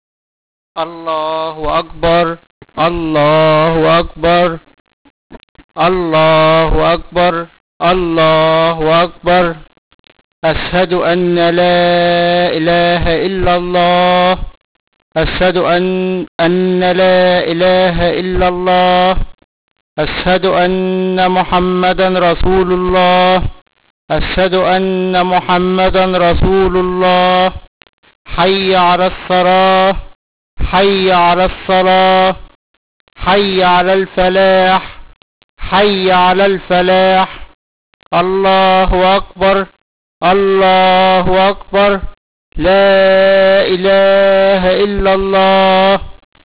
モスクから聞こえるお祈りの声です。
azhan1.wav